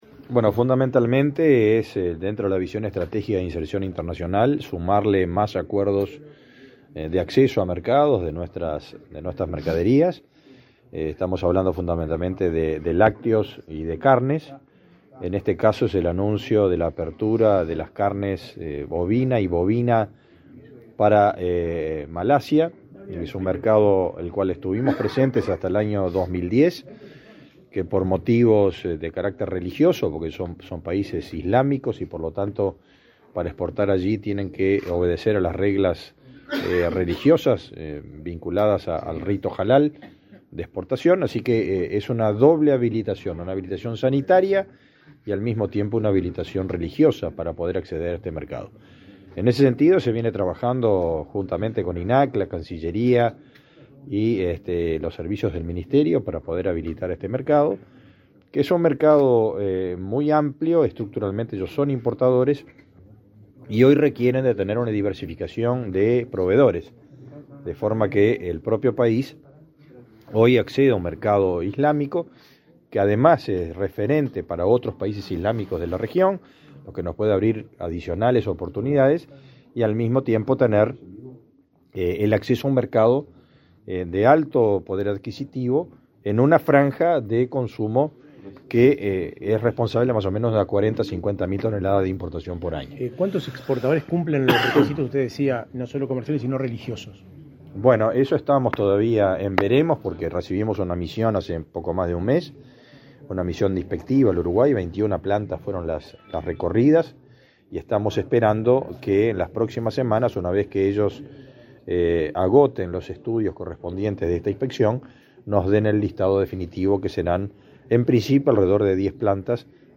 Declaraciones a la prensa del ministro de Ganadería, Fernando Mattos
Luego del evento, el ministro Fernando Mattos realizó declaraciones a la prensa.